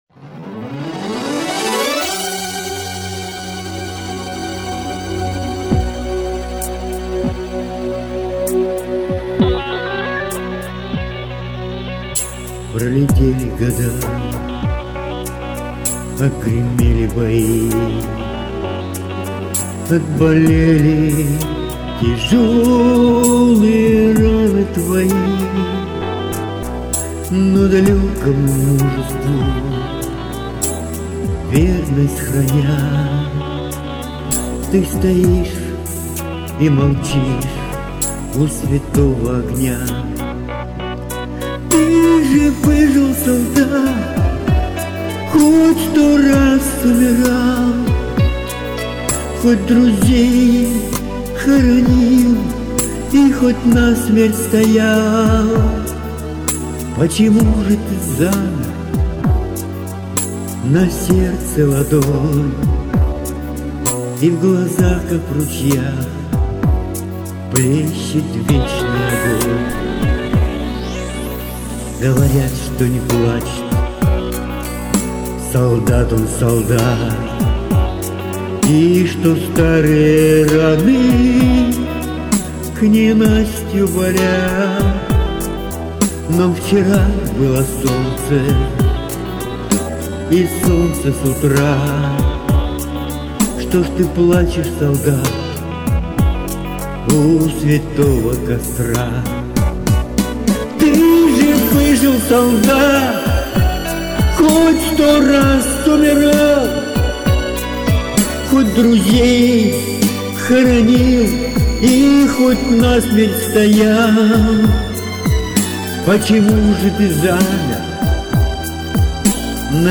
(Военные и Патриотические)